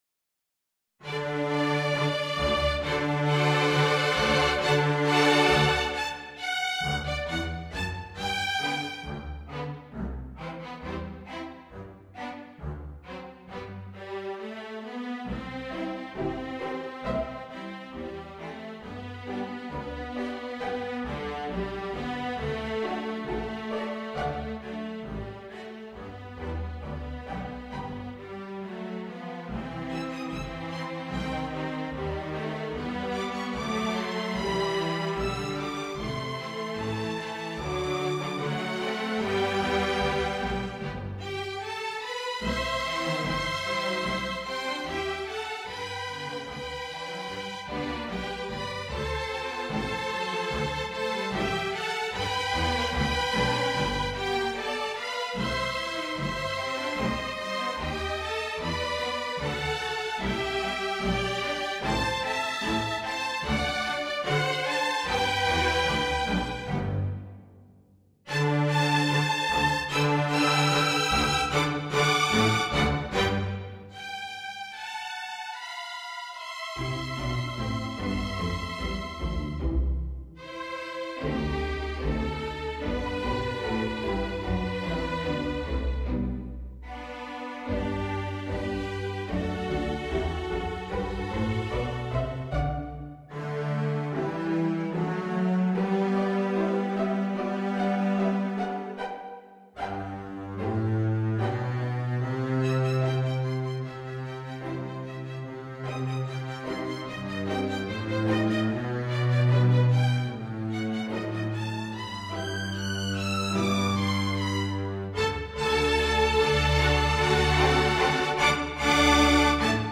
Voicing: String Orchestsra